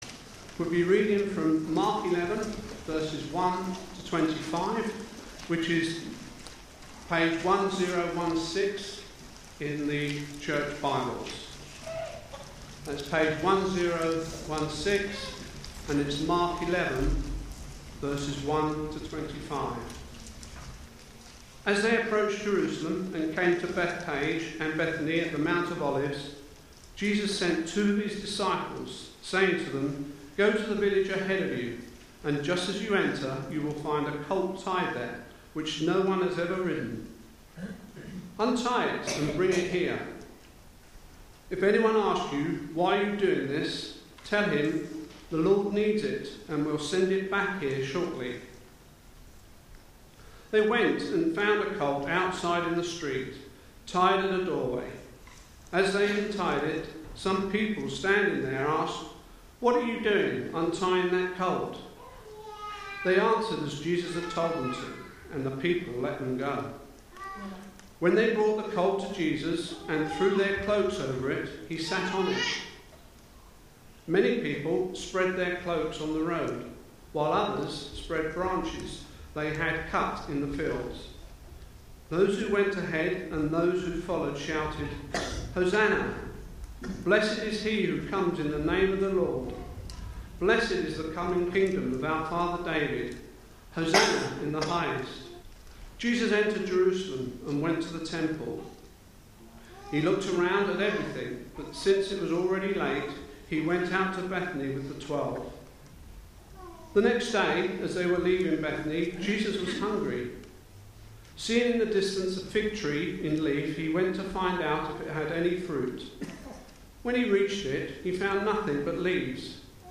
Media for Sunday Service
Sermon